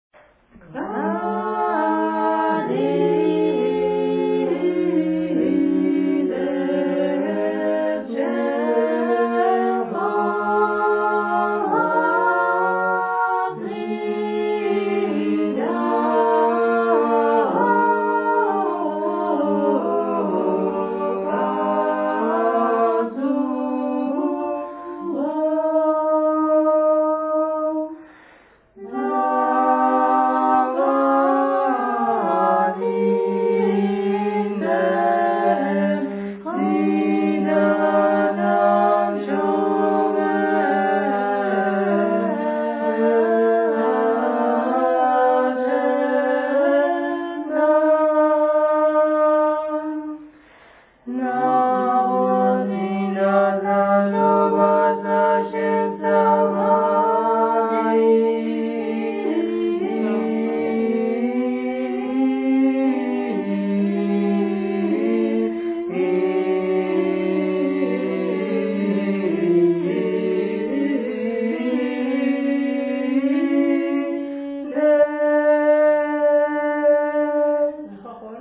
A particularly characteristic Guruli sagalobeli (Gurian chant/praise song) is the following